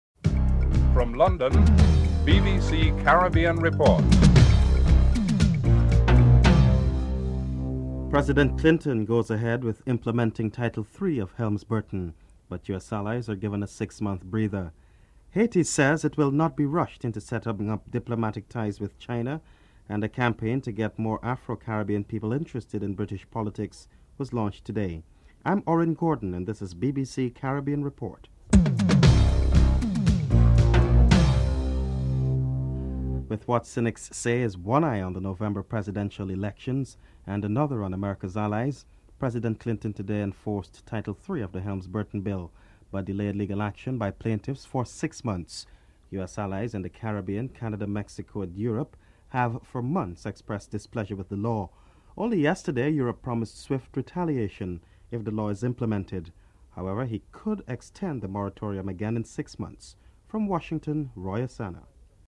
The British Broadcasting Corporation
1. Headlines (00:00-00:31)